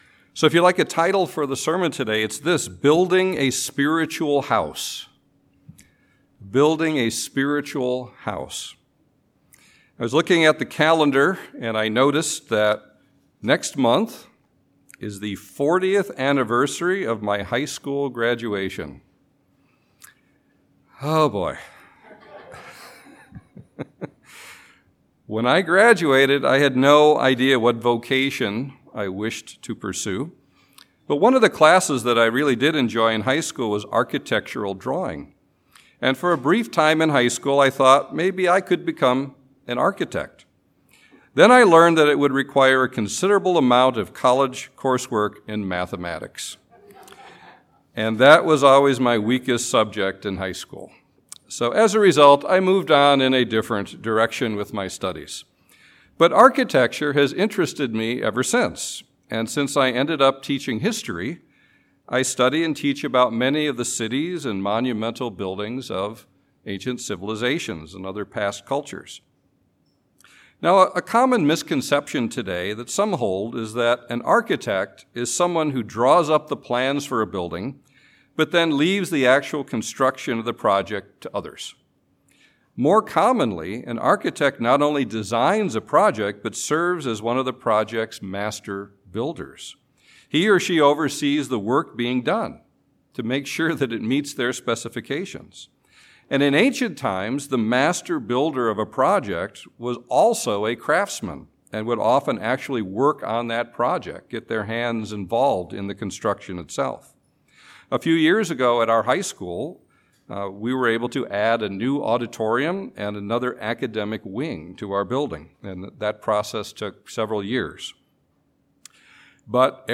Sermons
Given in Chicago, IL